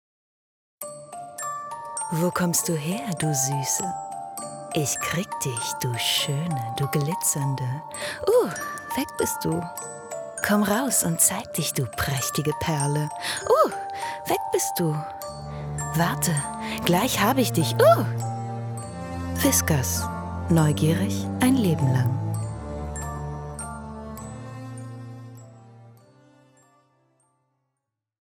Ihre Stimme ist freundlich, sachlich/kompetent, über erzählerisch, aber auch jung, werblich, lieblich oder verführerisch.
freundlich, warm, wandelbar, frech und verführerisch
Sprechprobe: Werbung (Muttersprache):
Werbung_Whiskas.mp3